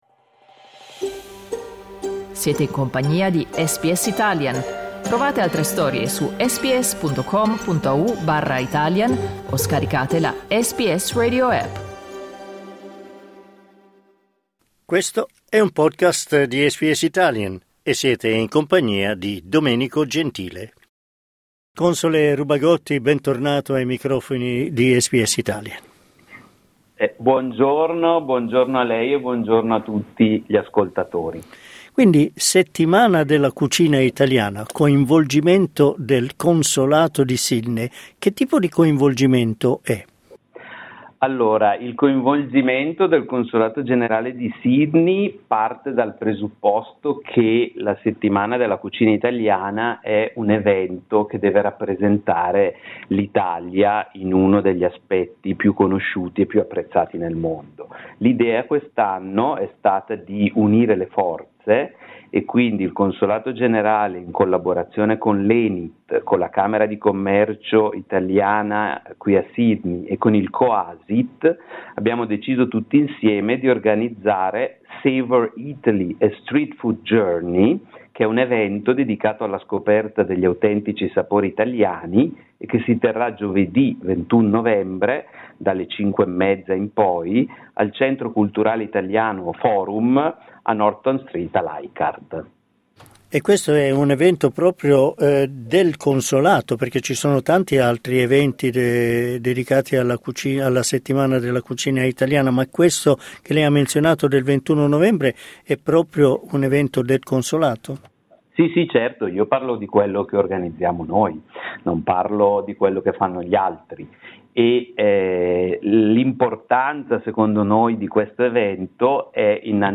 Clicca sul tasto "play" in alto per ascoltare l'intervista al console Rubagotti